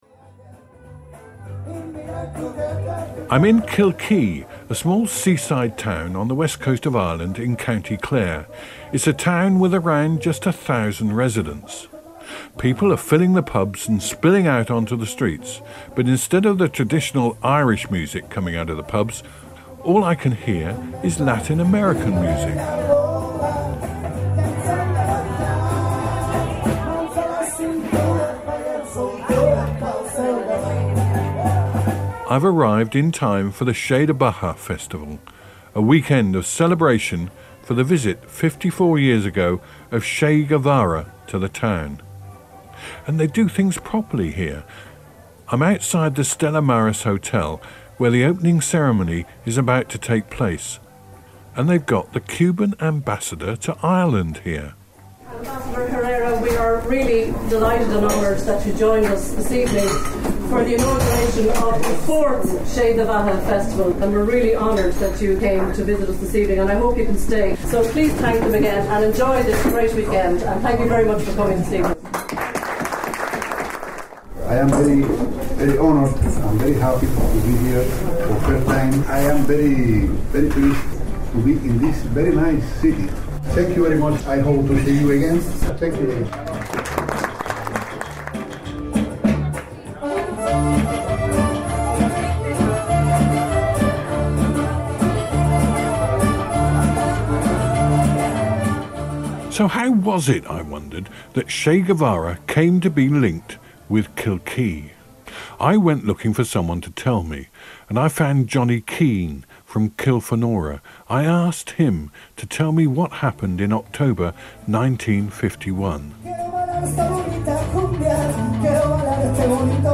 reports on the Che Do Bheatha Festival in Kilkee in Sept 2015